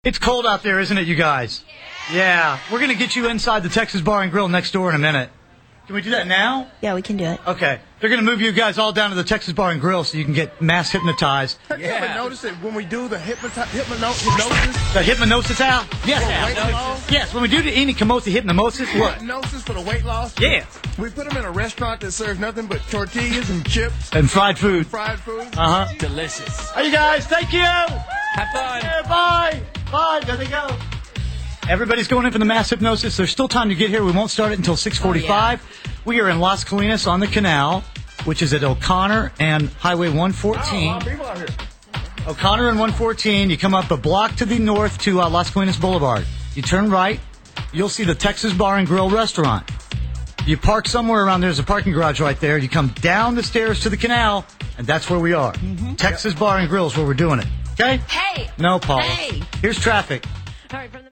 I’ve been featured on the nationally syndicated radio morning show “Kidd Kraddick in the Morning”.